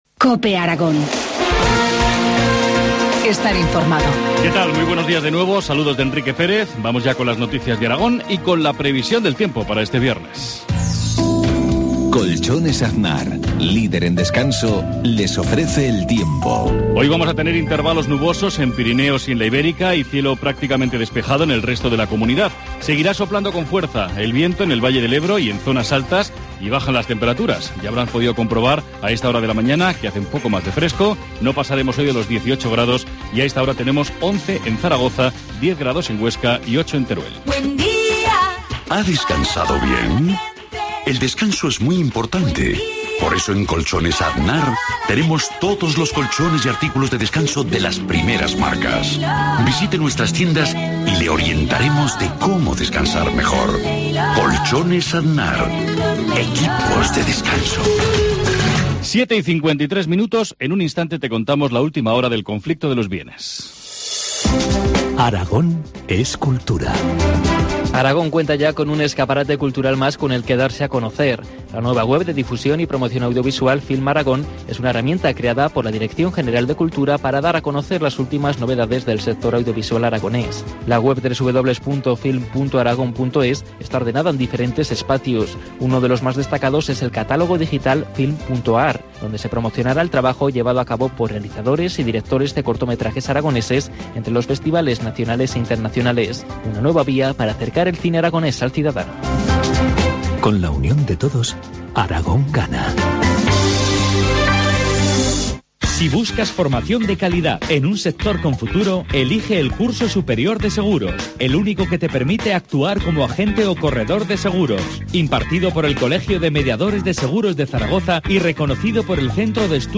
Informativo matinal, viernes 11 de octubre, 7.53 horas